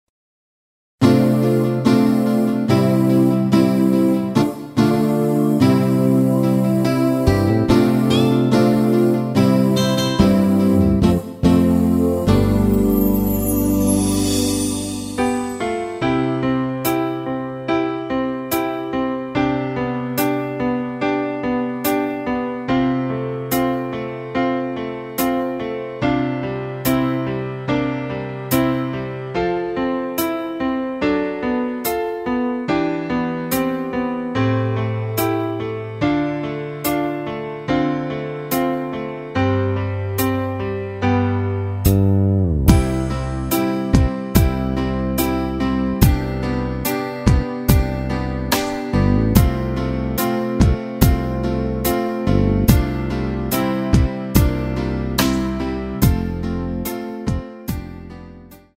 키 C
원곡의 보컬 목소리를 MR에 약하게 넣어서 제작한 MR이며